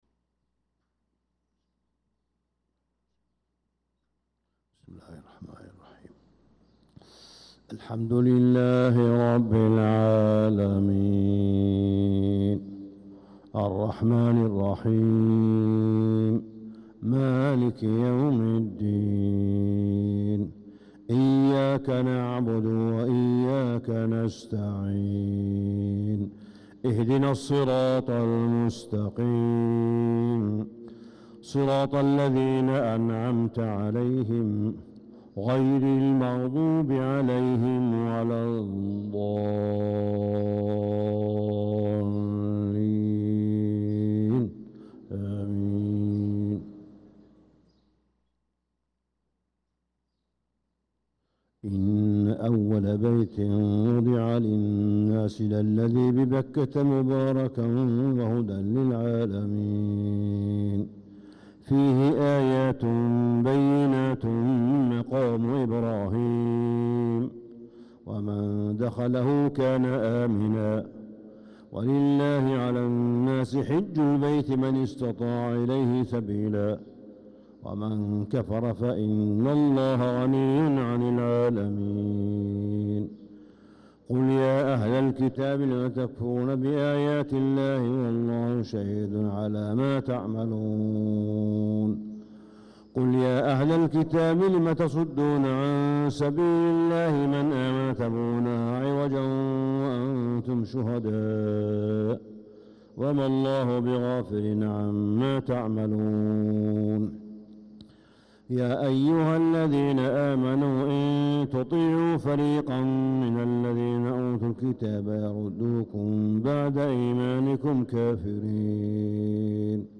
صلاة الفجر للقارئ صالح بن حميد 2 ذو الحجة 1445 هـ
تِلَاوَات الْحَرَمَيْن .